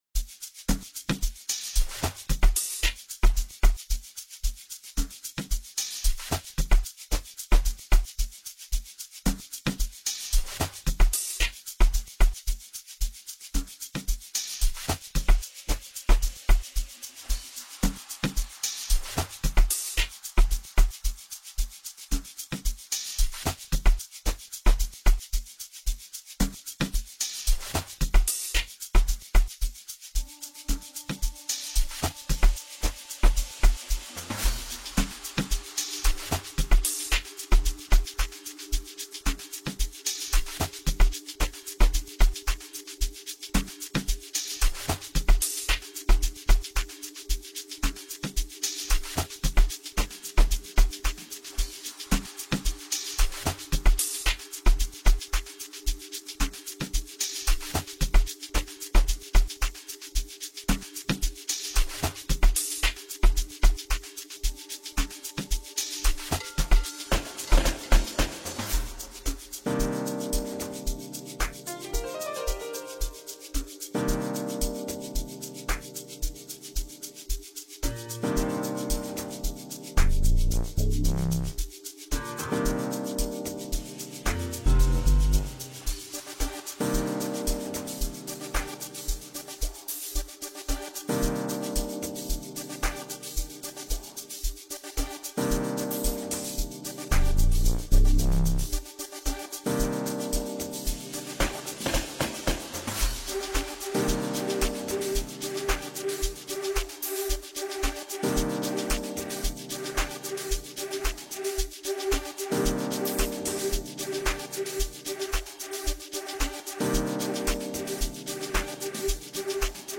Veteran producer
Amapiano